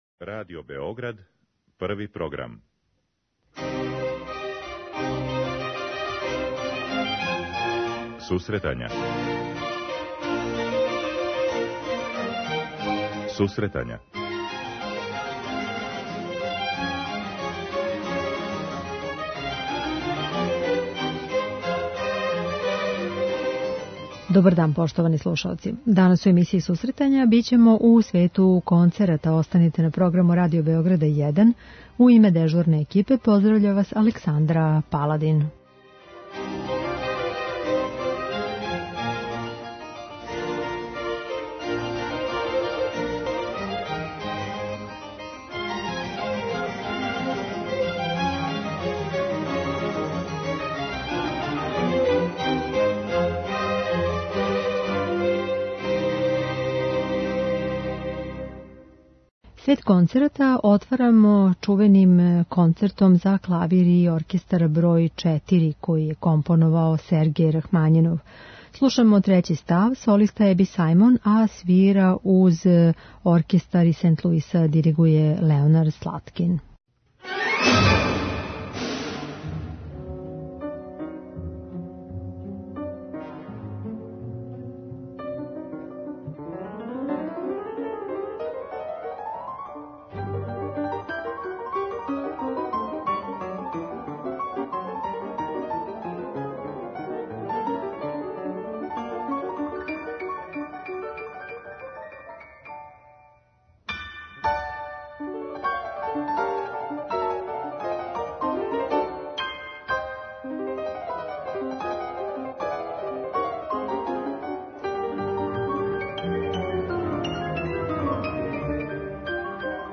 Концерт као музичка форма је изазовна и за композиторе, али и за извођаче. У извођењу сјајних солиста данас ћемо слушати одабране ставове из концерата за различите инструменте. То је музика из опуса Сергеја Рахмањинова, Макса Бруха, Вацлава Штамица, Волфганга Амадеуса Моцарта и Антоњина Дворжака.